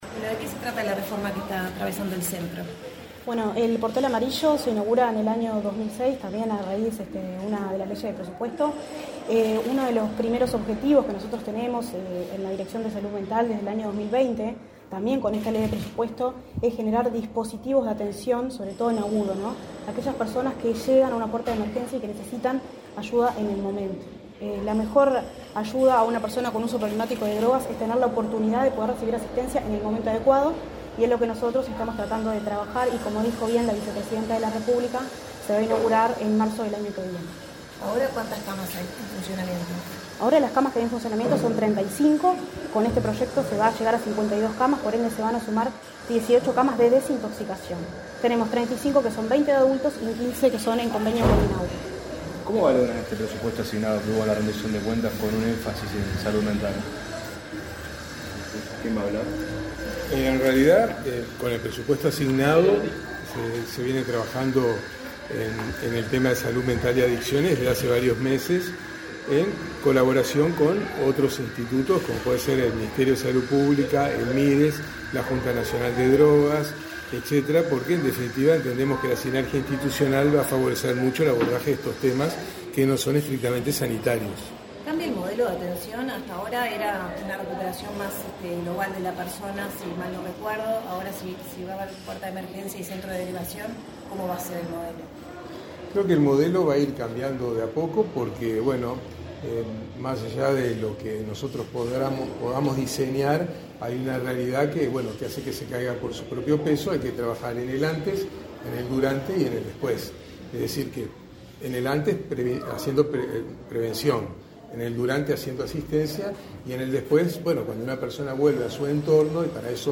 Declaraciones a la prensa de autoridades de Salud Mental de ASSE
La presidenta de la República en ejercicio, Beatriz Argimón, recorrió, este 20 de noviembre, las obras del Centro de Información y Referencia de la Red Drogas Portal Amarillo. Tras la visita, el director y la subdirectora de Salud Mental de la Administración de los Servicios de Salud del Estado (ASSE), Eduardo Katz y Jimena Píriz, realizaron declaraciones a la prensa.